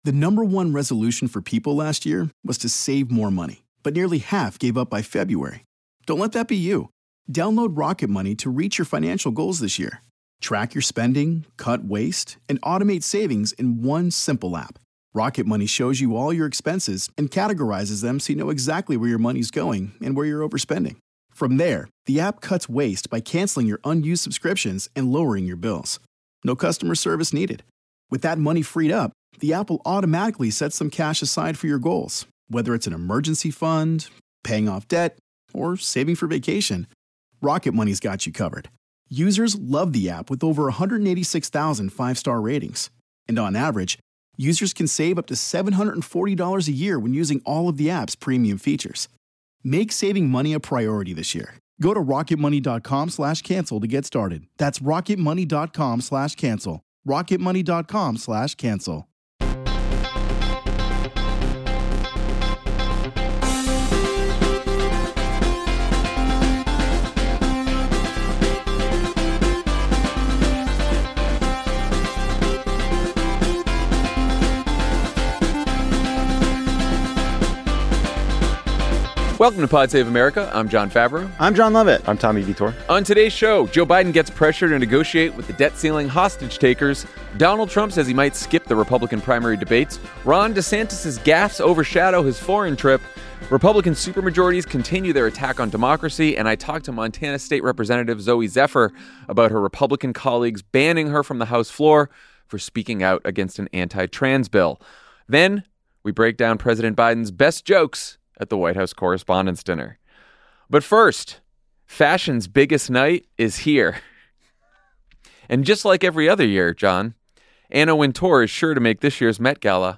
Montana state Representative Zooey Zephyr joins to talk about her Republican colleagues banning her from the House floor for speaking out against an anti-trans bill. Then, Jon, Jon, and Tommy break down President Biden’s best jokes at the White House Correspondents Dinner.